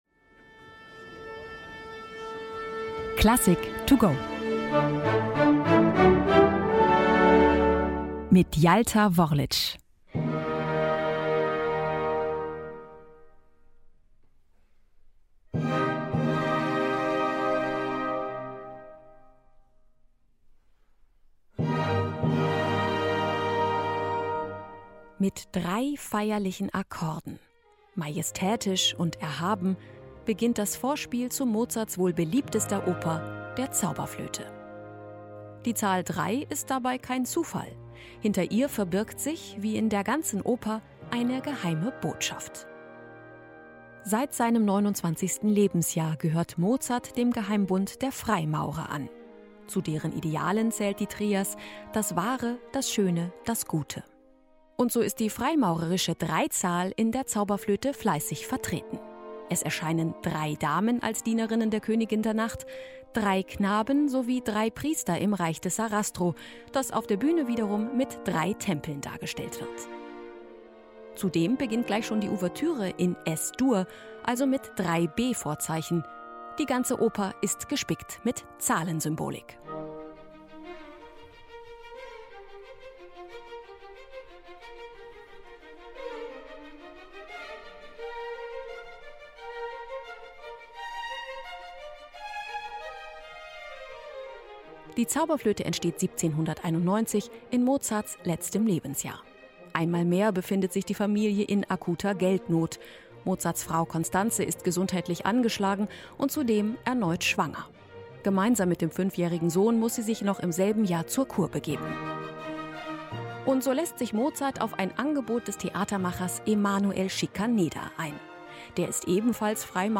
kurzen Werkeinführung